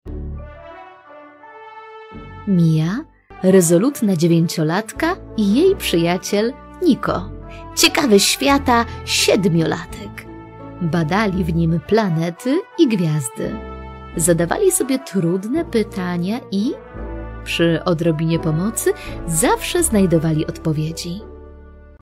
Wykonuję również dubbing do gier i animacji.